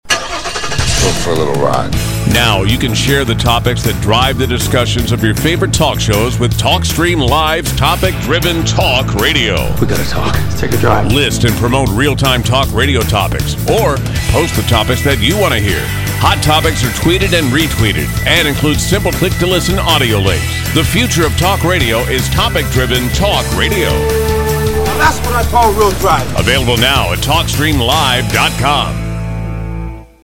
TSL spot.